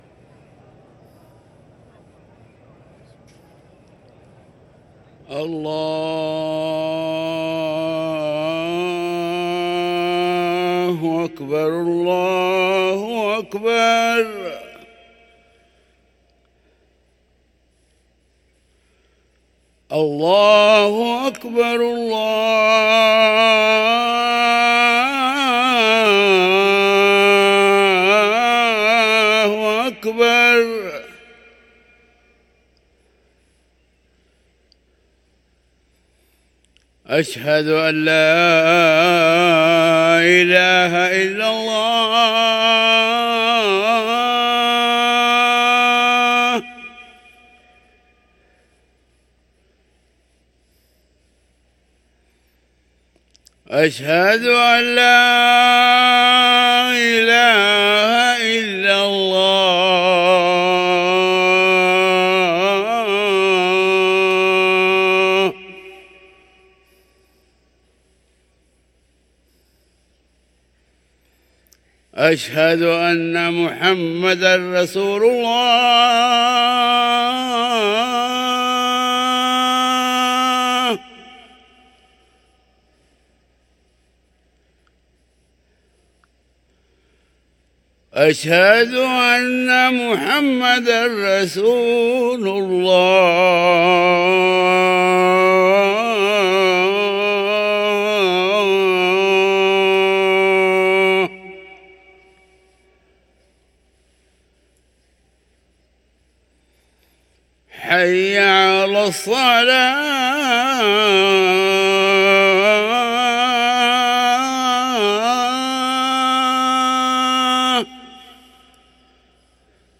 أذان الفجر
ركن الأذان